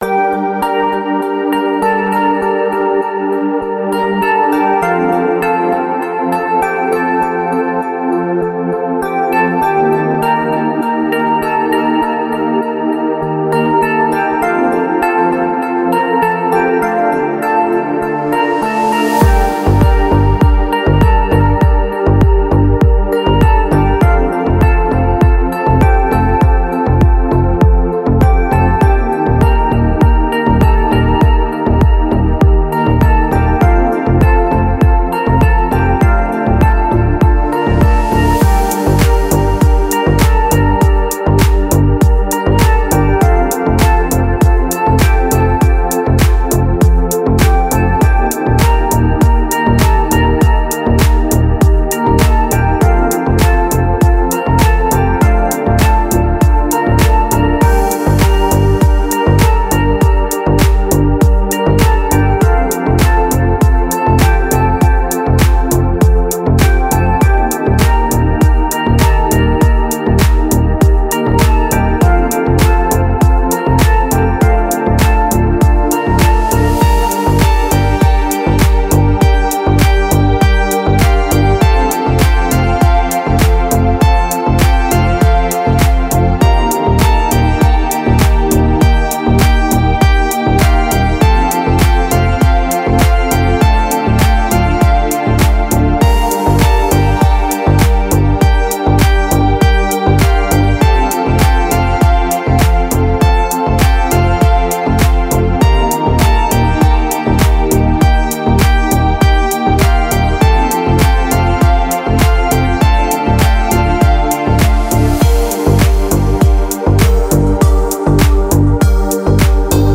Мелодичные треки